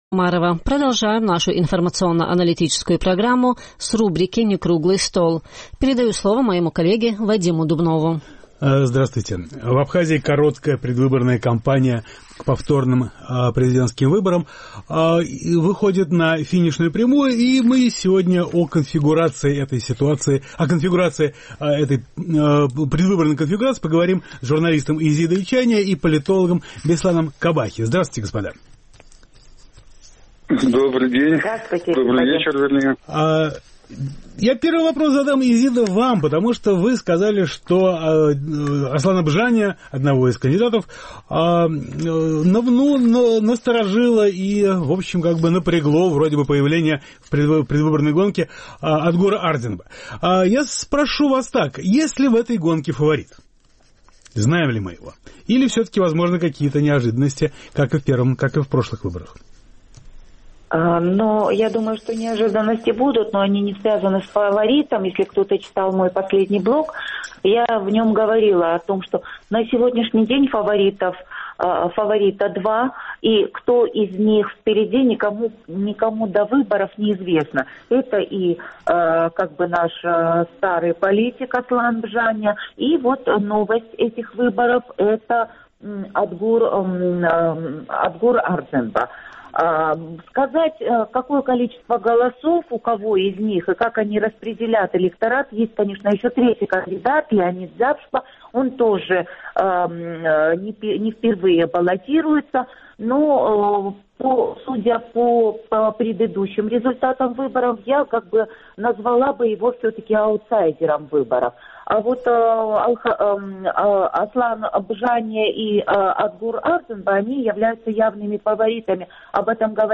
Некруглый стол